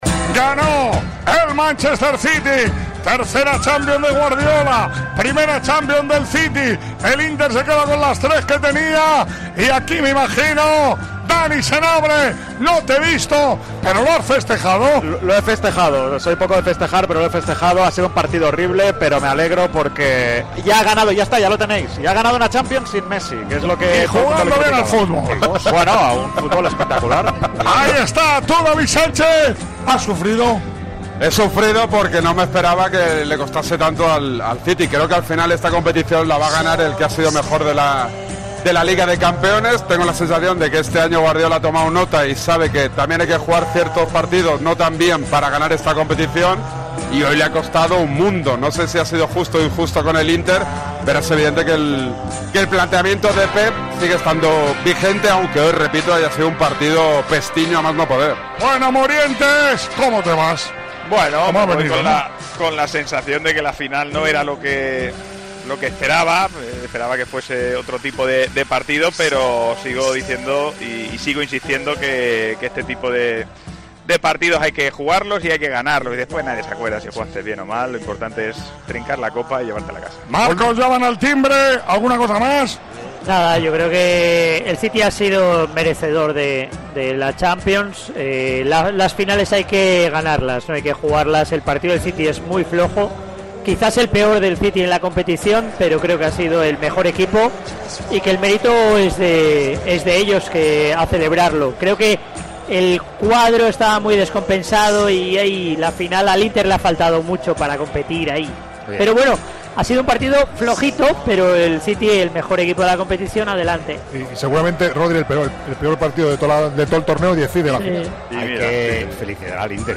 Los comentaristas de Tiempo de Juego analizan el triunfo del City: "¿Se terminarán las críticas a Guardiola?"